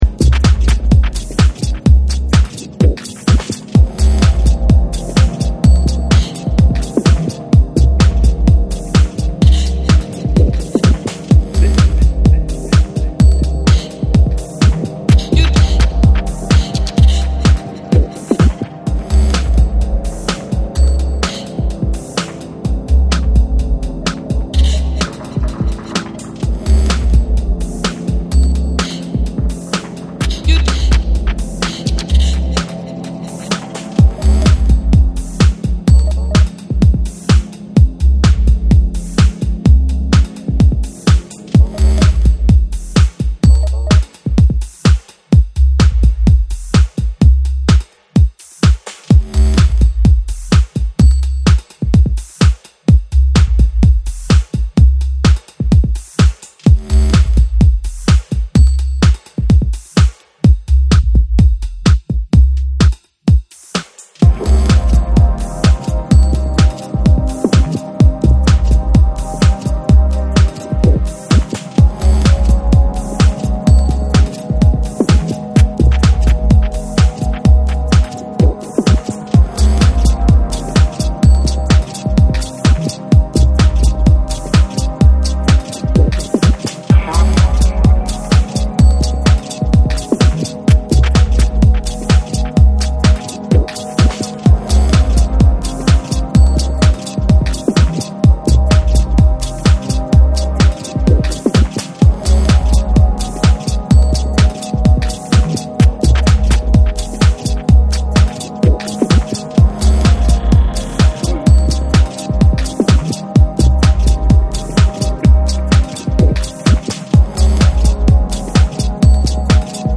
Hypnotic grooves and deep evolving atmospheres